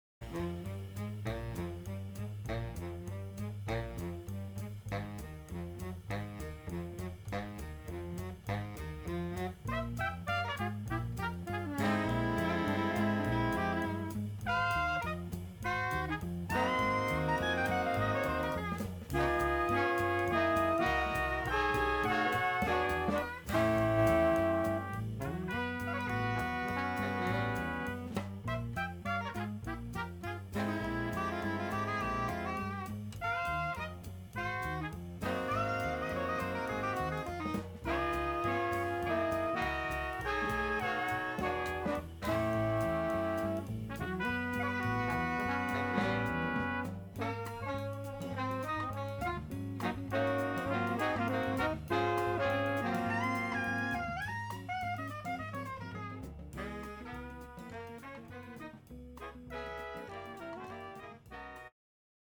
Recorded Master Chord Studios January 2017